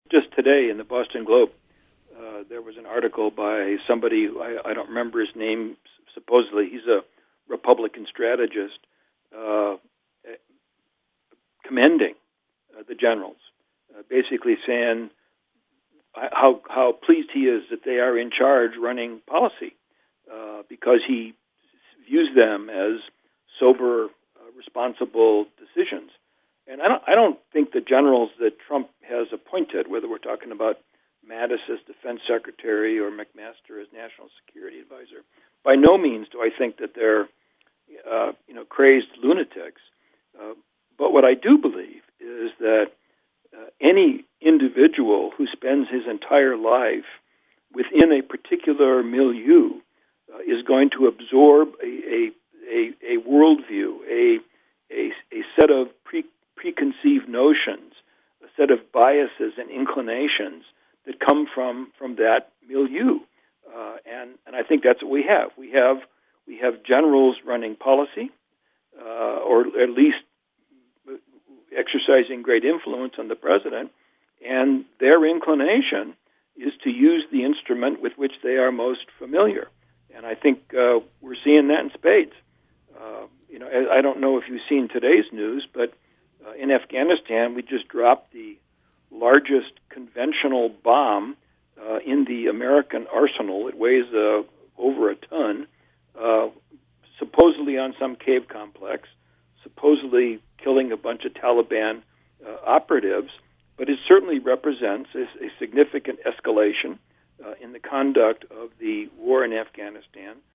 In-Depth Interview: Prof. Andrew Bacevich Comments On Trump’s War in Syria